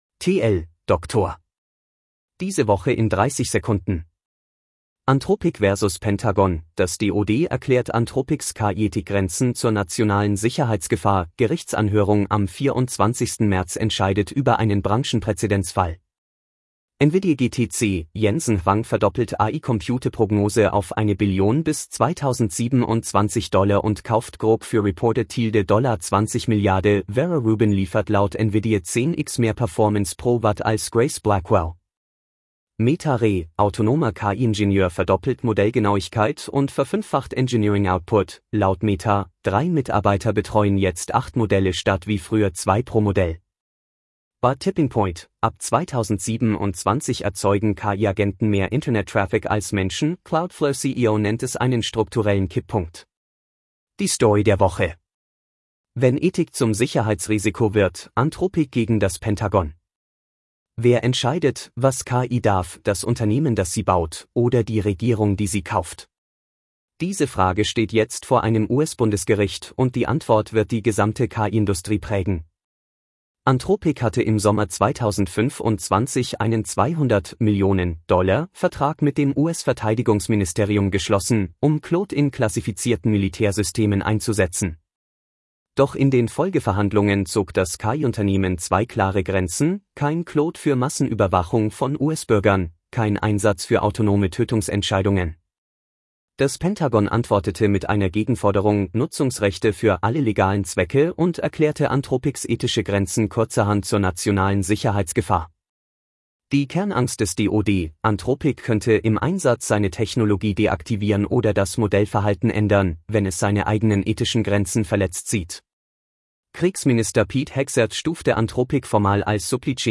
Vorgelesen mit edge-tts (de-DE-ConradNeural)